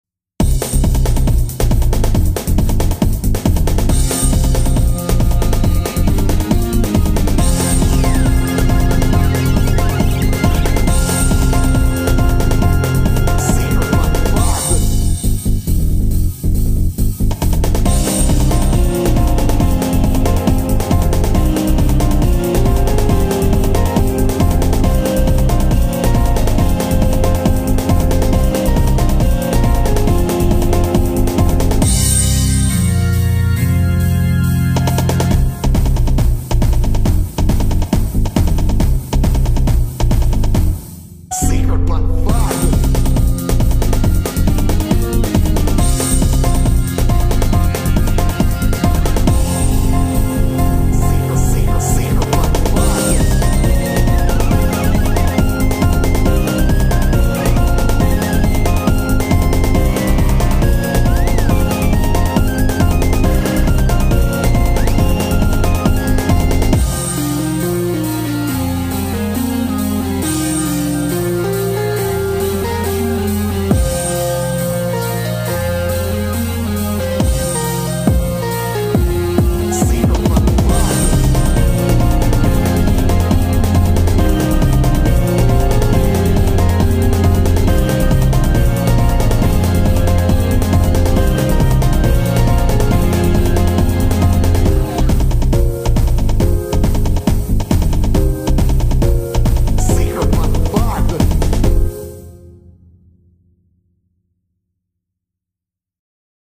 So i added some different samples, and voila!